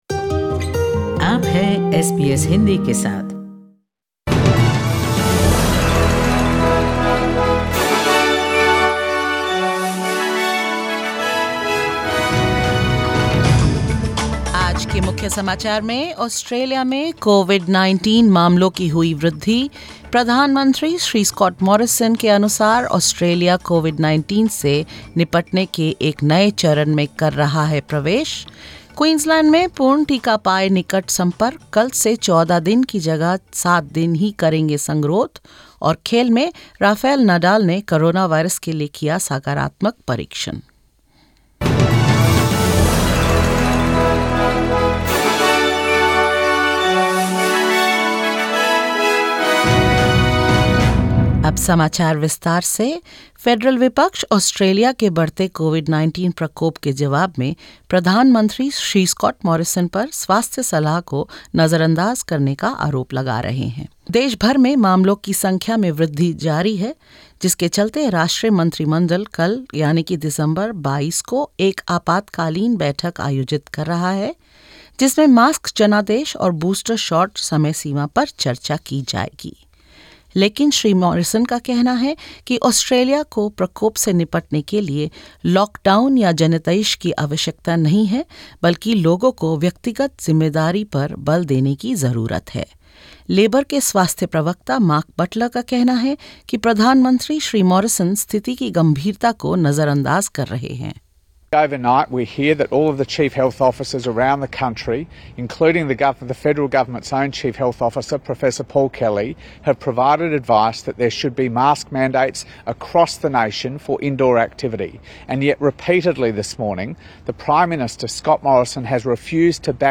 In this latest SBS Hindi news bulletin: Prime Minister Scott Morrison pushes back against more lockdowns and mask mandates, urges Australians to take ‘personal responsibility’; Queensland cuts quarantine time for fully vaccinated, close contacts of COVID-19 cases; Rafael Nadal tests positive for Coronavirus and more.